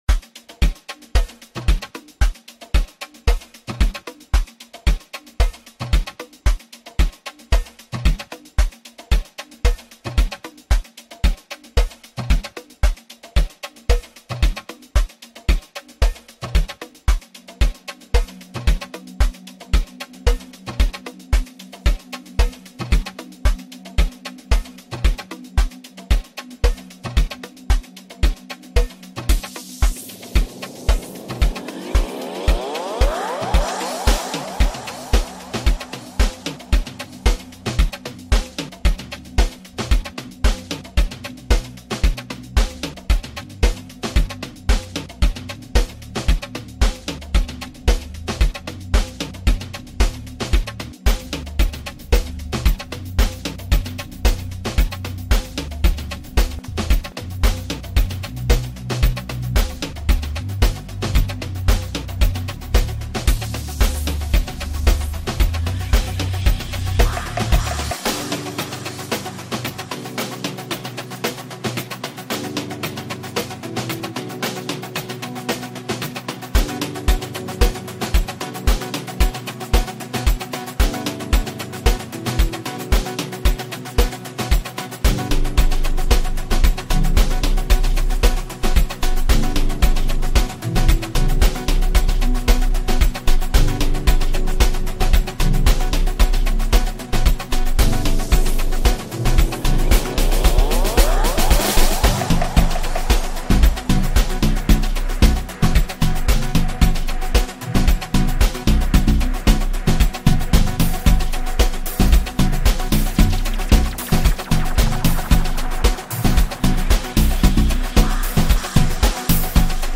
Amapiano, DJ Mix, Hip Hop
South African singer-songwriter